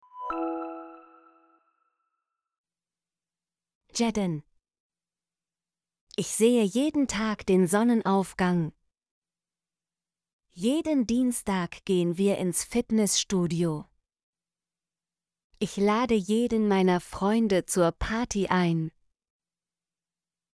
jeden - Example Sentences & Pronunciation, German Frequency List